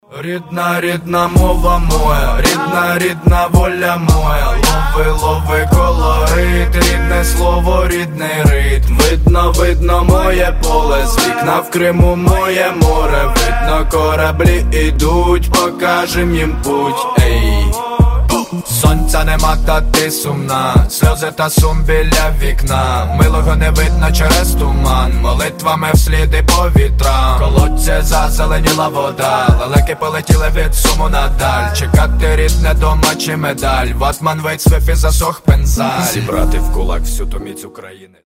• Качество: 320, Stereo
душевные